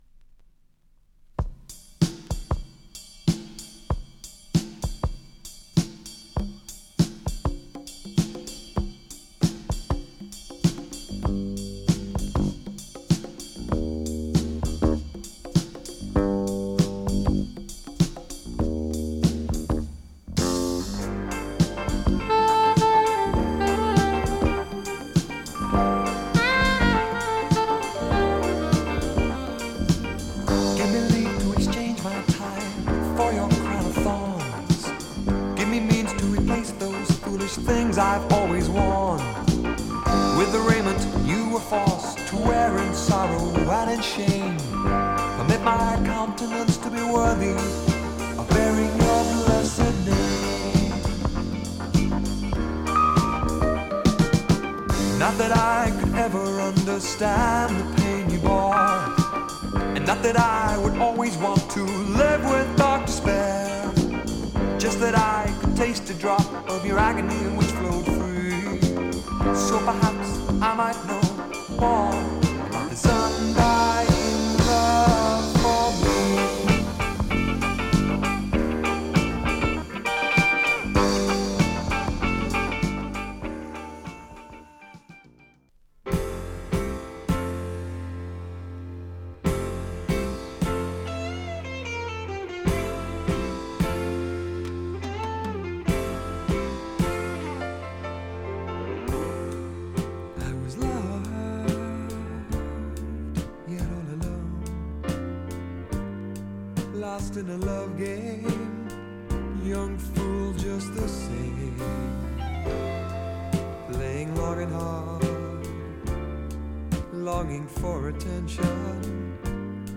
甘美でメロウなアーバン・メロウ
ミディアム・ライト・ファンキーメロウ
イントロの煌きのエレピが摩天楼へと誘う極上メロウ・フローター